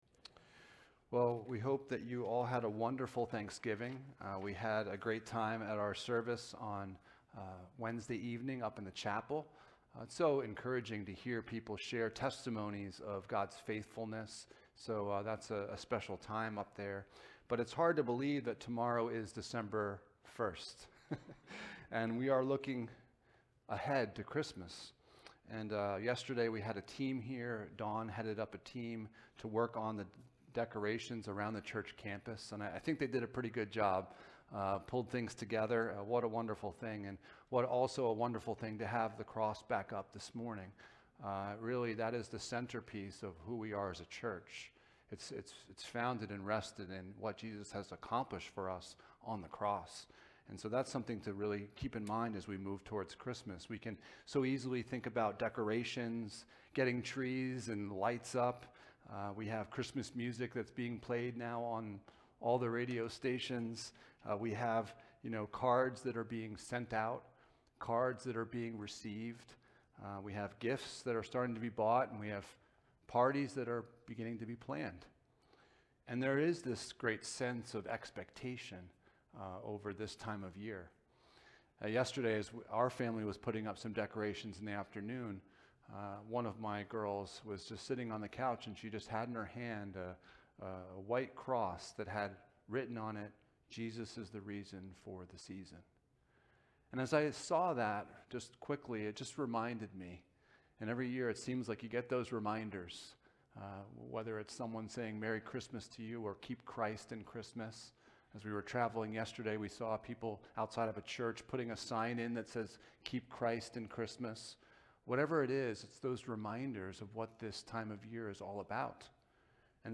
Passage: Psalm 130, Matthew 1 Service Type: Sunday Morning